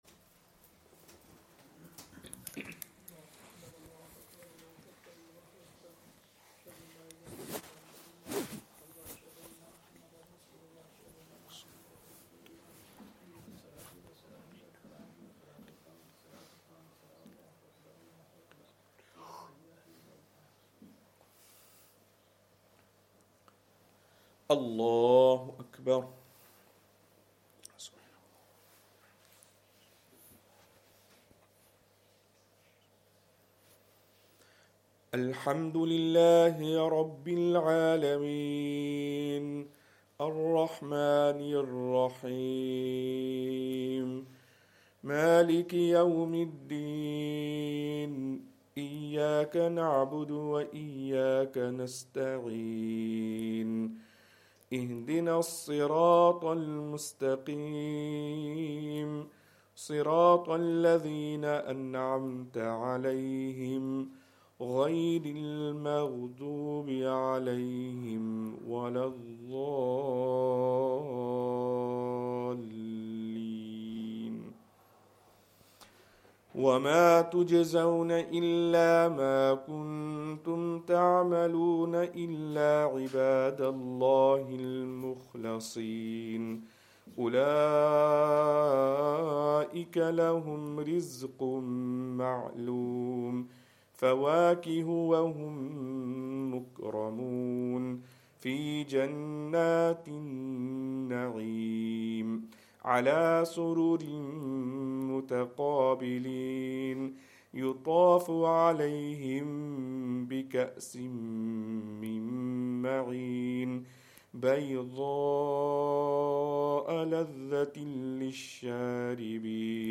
Fajr Surah As Saaffaat
Madni Masjid, Langside Road, Glasgow